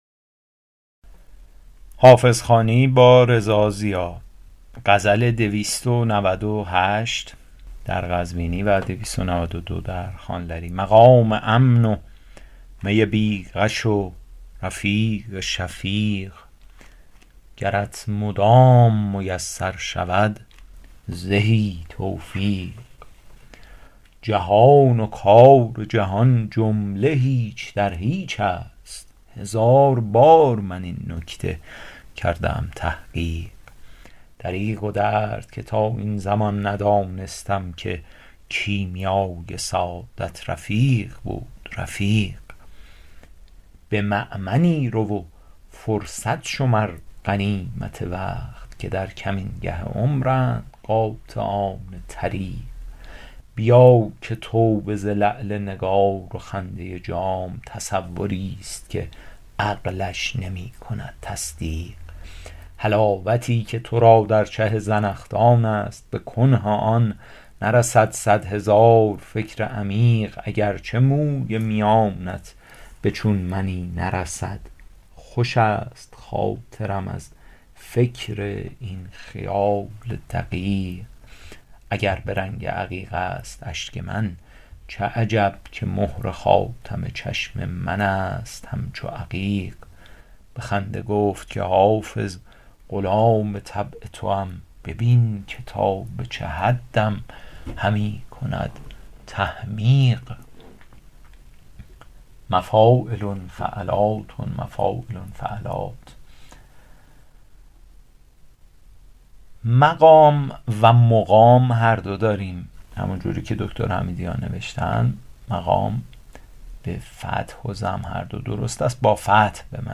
شرح صوتی غزل شمارهٔ ۲۹۸